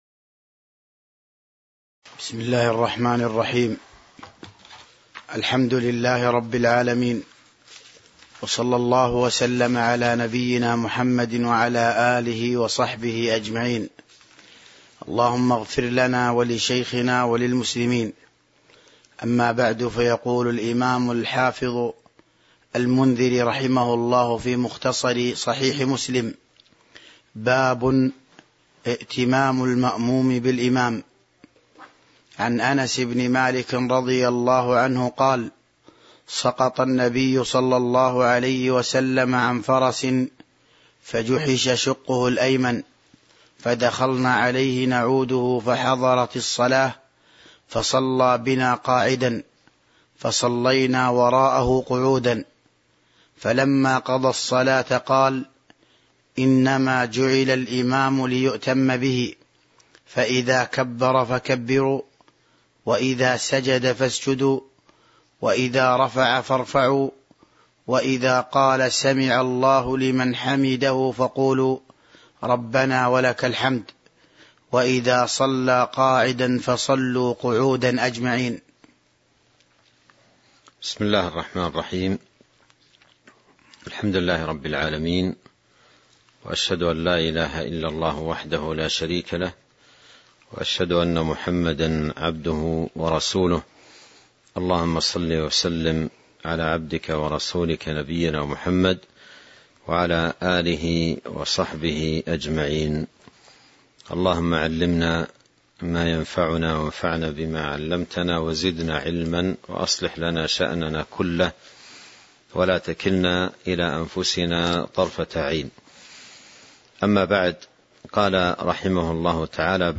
تاريخ النشر ٢٩ ربيع الثاني ١٤٤٢ هـ المكان: المسجد النبوي الشيخ: فضيلة الشيخ عبد الرزاق بن عبد المحسن البدر فضيلة الشيخ عبد الرزاق بن عبد المحسن البدر باب ائتمام المأموم بالإمام (015)